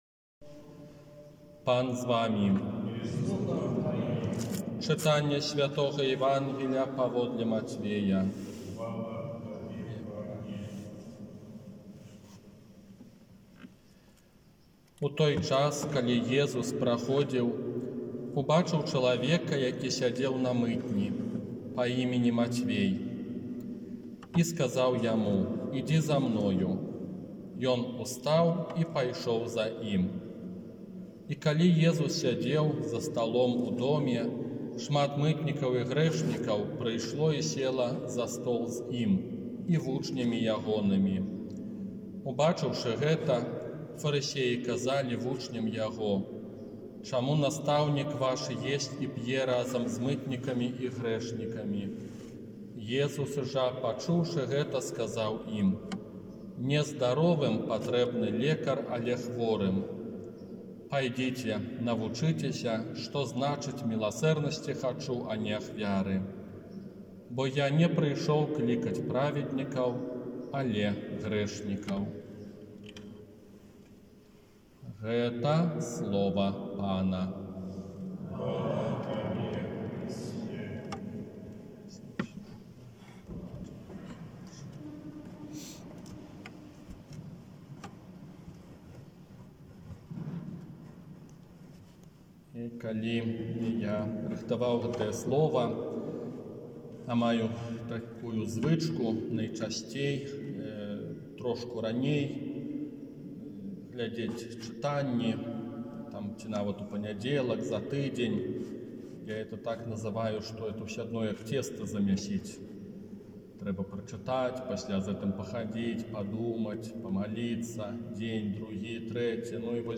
ОРША - ПАРАФІЯ СВЯТОГА ЯЗЭПА
Казанне на дзесятую звычайную нядзелю